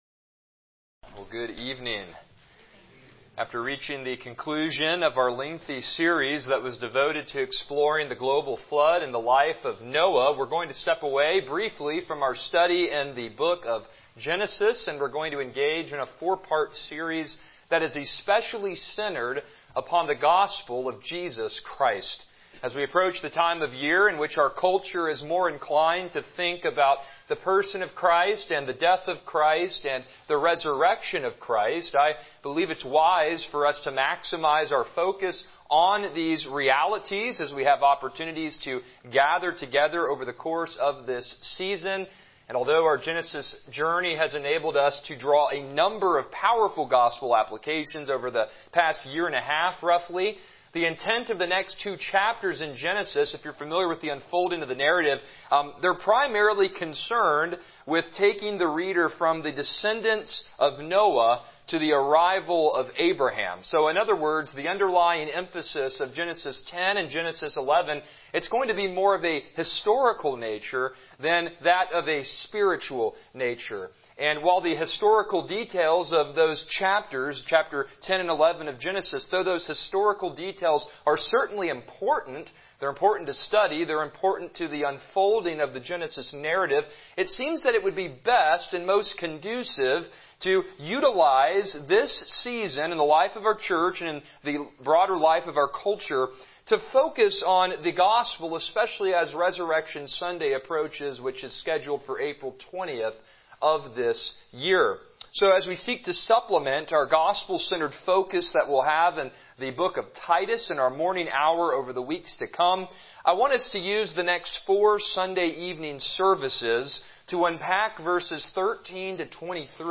Passage: Colossians 1:13-14 Service Type: Evening Worship